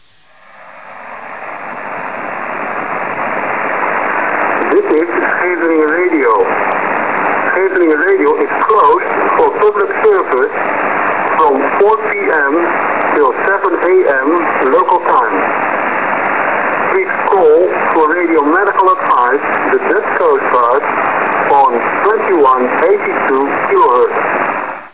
On this page some sounds can be heard for those who would like to hear these radio sounds from the past.
PCHtape is the tape run in phone when the station was closed for public service (This hapend only in the last years of operation!)